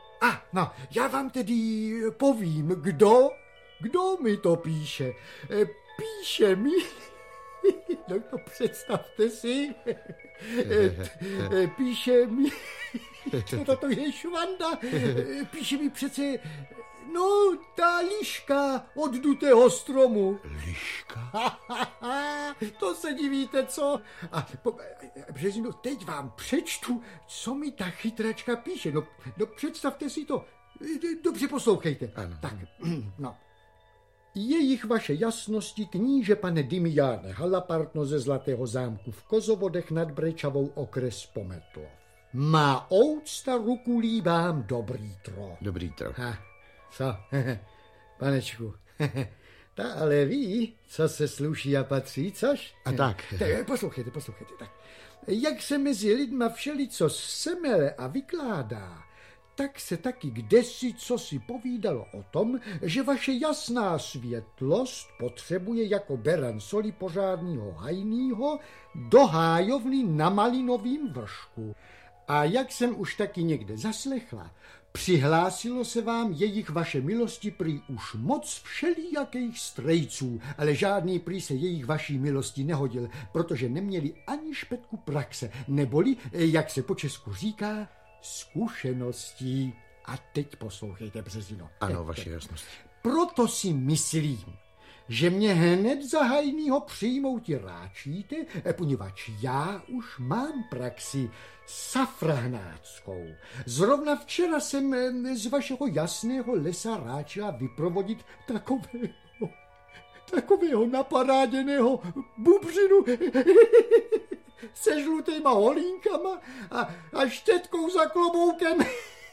Audio kniha
Ukázka z knihy
Alena Vránová předala postavě lišky svůj šarm, bystrost a vtip. Také ostatní interpreti dotvářejí s humorem pohodu kouzelného světa Ladovy pohádky!